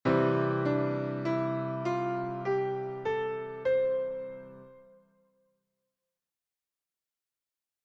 Gospel Piano
Gamme Gospel Majeure
La gamme gospel de base est une gamme hexatonique ( 6 tons ).
Il s’agit de la gamme majeure à laquelle on a enlevé la 7ème.
gamme-gospel.mp3